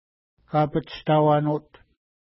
Kapatshitauanut Next name Previous name Image Not Available ID: 523 Longitude: -60.0433 Latitude: 53.4851 Pronunciation: ka:pətʃta:wa:nu:t Translation: Where People Set Nets Official Name: Gibeon Point Feature: point